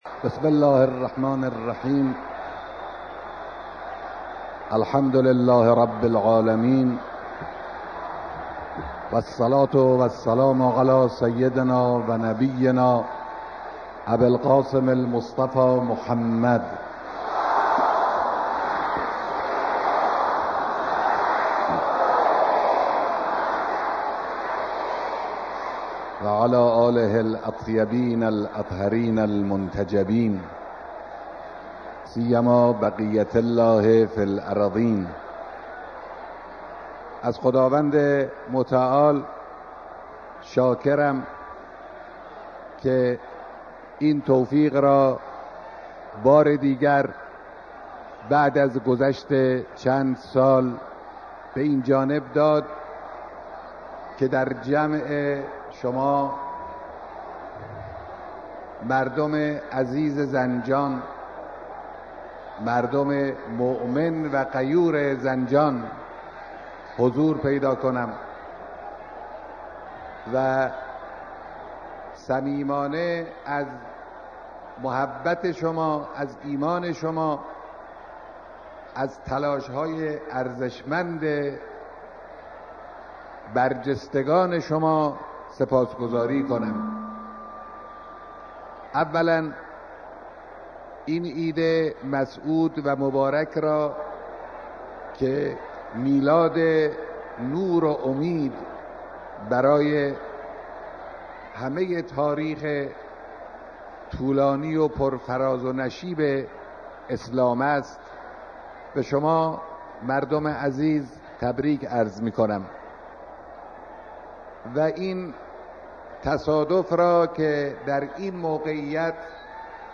سخنرانی رهبر انقلاب در اجتماع مردم زنجان
بیانات در اجتماع مردم زنجان